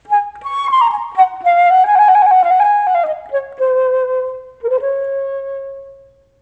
barflute.mp3